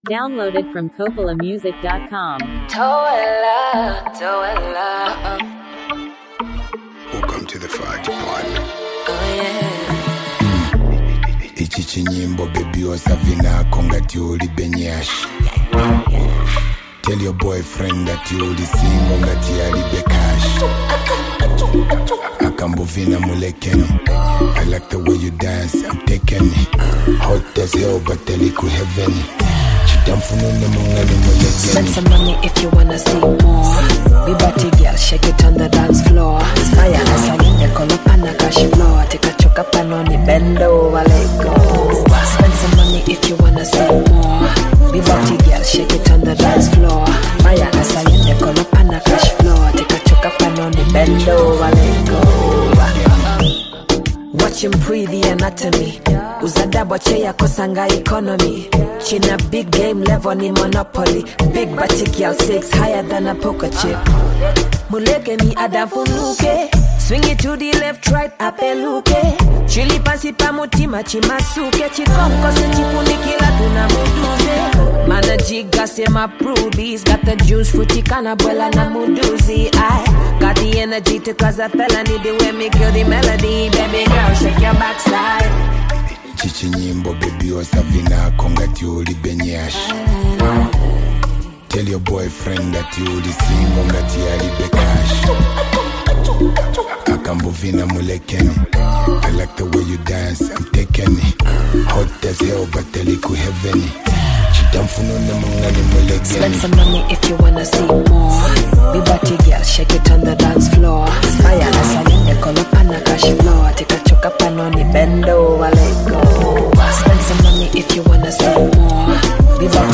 smooth and soulful voice